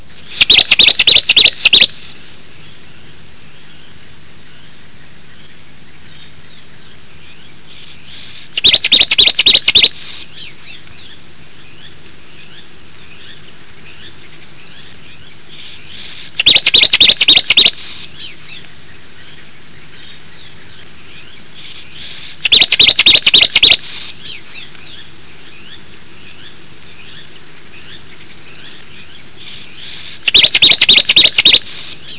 Una de ellas parece ser el Trile (Agelaius thilius)(canto: Brebe(114 Kb) Completo (797 Kb)), ave palustre y, posiblemente otro, un ermitaño misterioso de la floresta austral, el Colilarga (Sylviorrhorhynchus desmursii) (canto:Brebe(54 Kb)